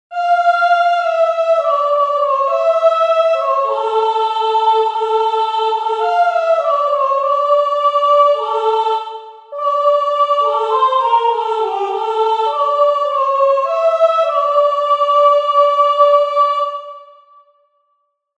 только соло